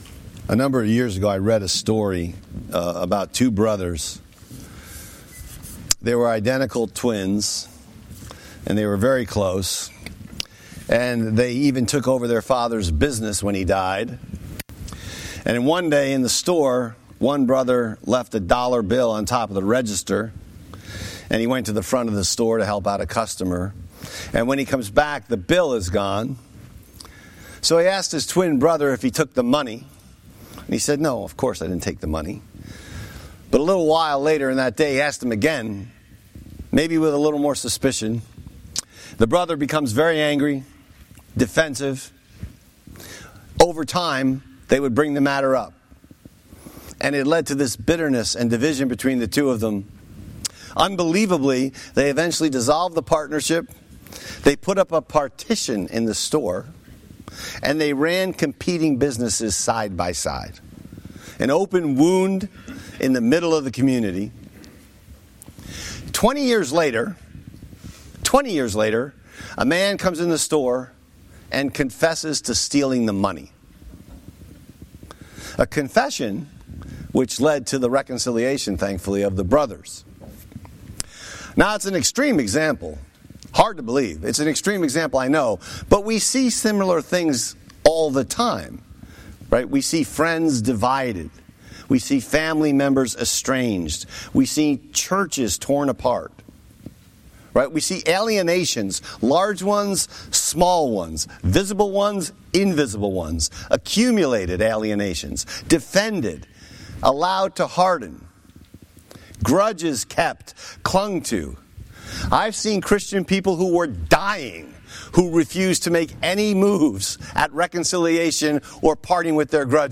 Sermon Text: Matthew 5:1-2